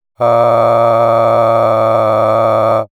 Physics-based simulation of disordered vocal timbres generated by SimuVox for clinical training and perceptual research.
Each sample uses the same utterance to highlight the differences in voice quality.
Jitter:
• Jitter: Modeled by introducing random perturbations in vocal fold stiffness or mass
male_jitter.wav